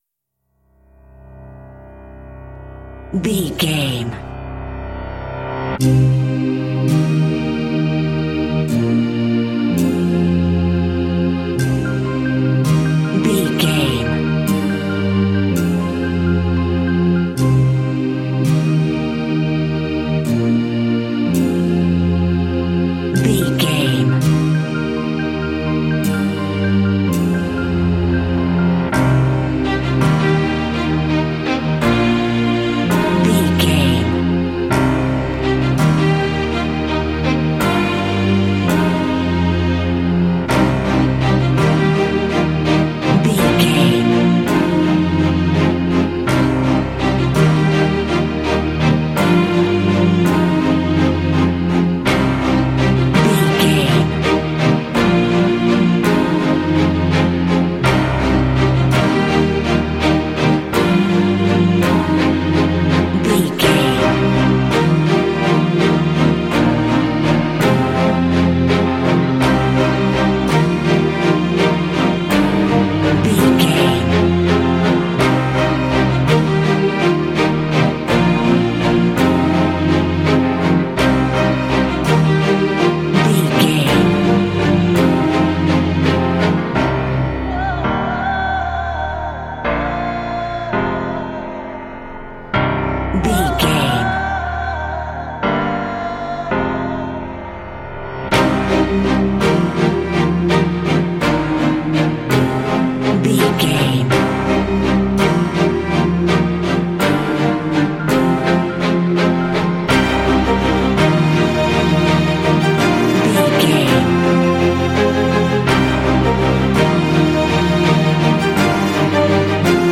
Epic / Action
Aeolian/Minor
powerful
inspirational
symphonic rock
cinematic
classical crossover